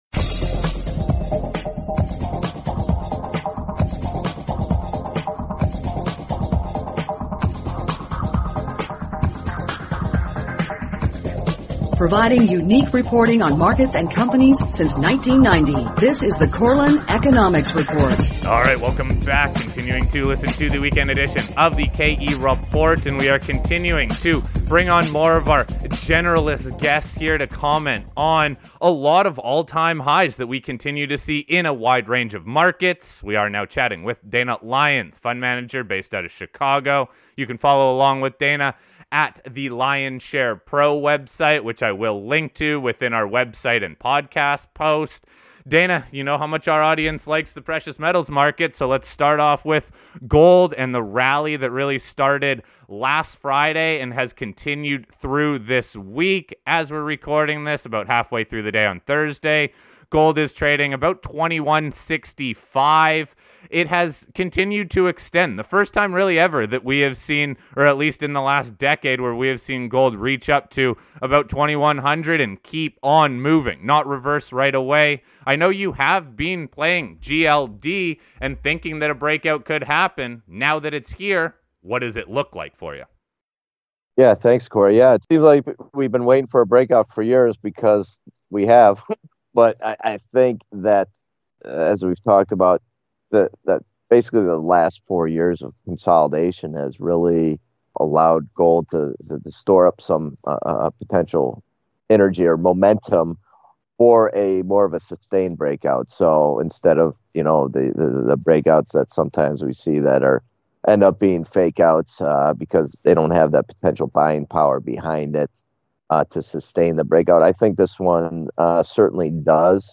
Welcome to The KE Report Weekend Show. There are a lot of positives to discuss for the markets so we are featuring 2 generalist investors to get their thoughts on a number of all-time highs. We focus on gold, Bitcoin and a wide range of sectors within the markets that are all in significant up-trends.